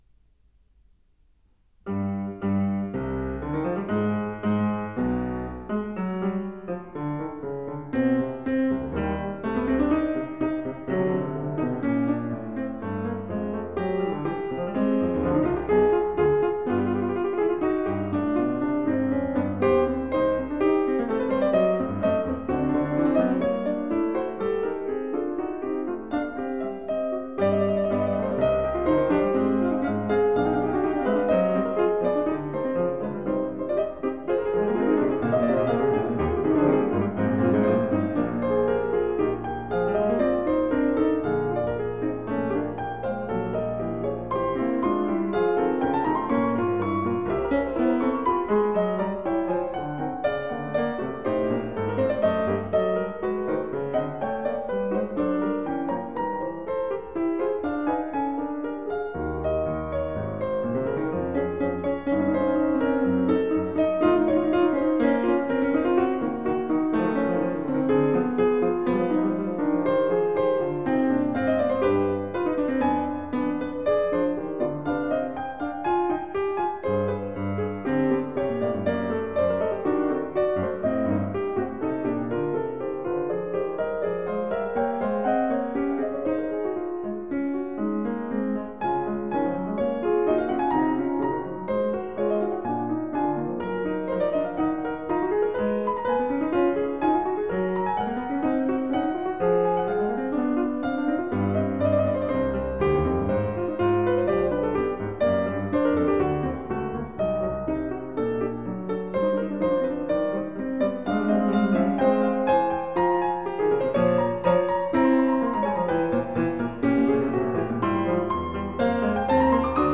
Piano Works/Piano Duets